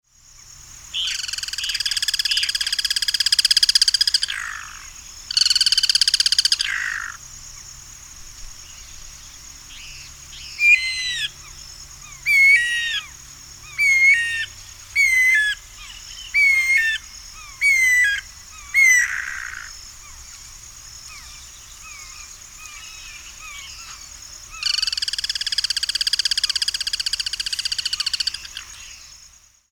Nome Científico: Guira guira
Nome em Inglês: Guira Cuckoo
Aprecie o canto do
Anu-branco